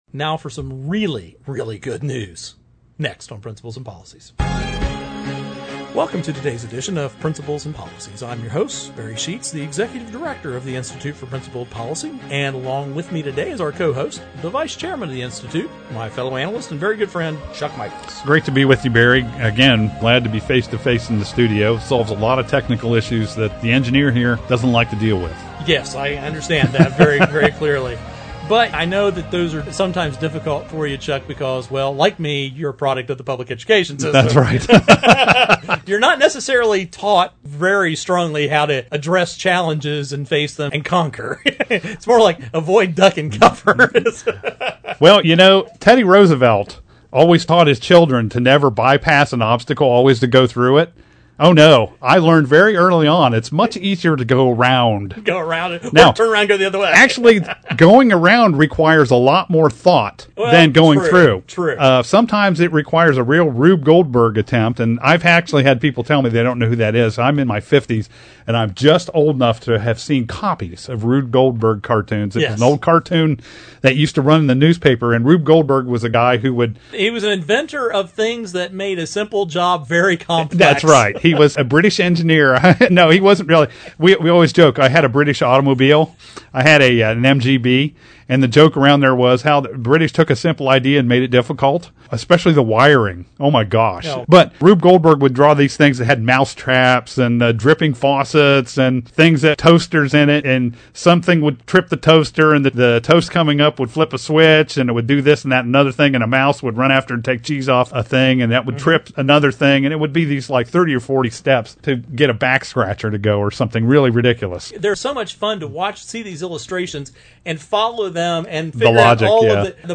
Our Principles and Policies radio show for Friday June 14 June 11, 2013.